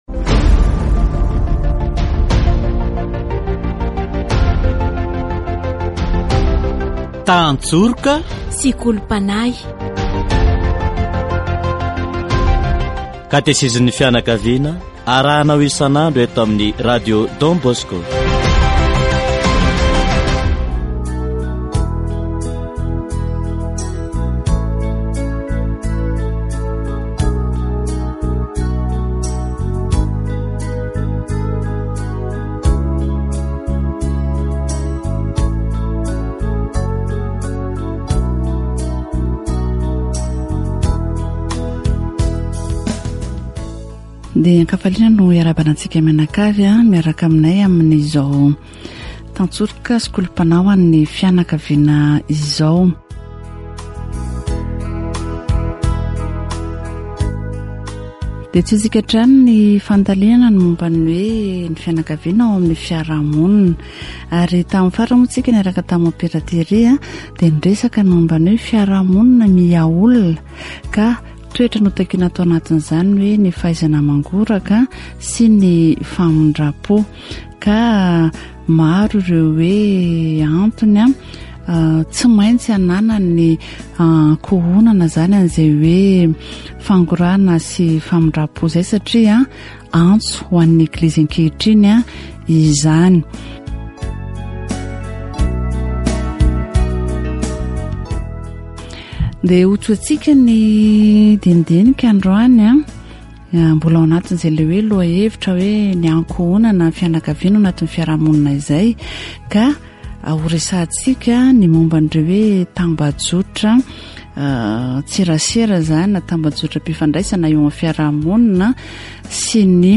Catégorie : Approfondissement de la foi
Catéchèse sur Facebook et internet